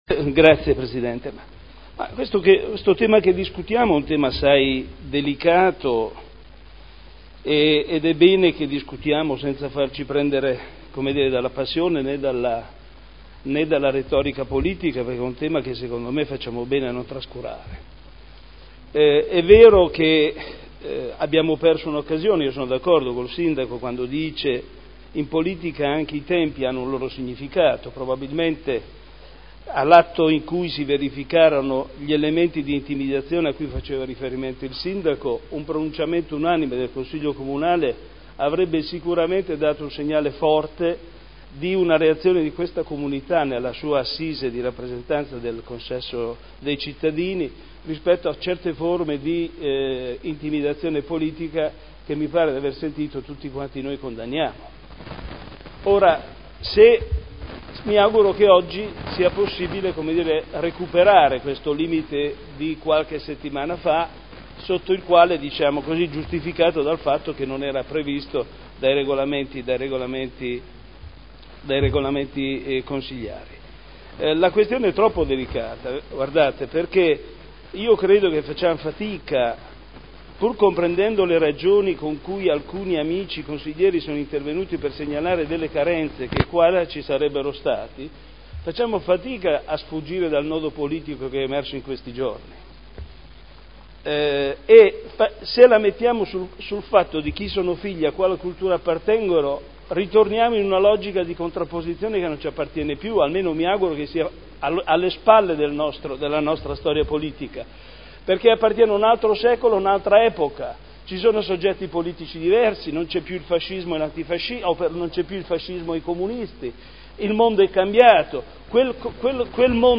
Seduta del 30/05/2011. Dibattito su: mozione presentata dai consiglieri Trande (P.D.), Ricci (Sinistra per Modena) e dalla Presidente del Consiglio Caterina Rita Liotti, avente per oggetto: “Condanna degli atti di intolleranza e solidarietà al Sindaco, al P.D. e alla CISL”, interrogazione del consigliere Morandi (PdL) avente per oggetto: “L’occupazione dell’ex cinema Scala quando terminerà.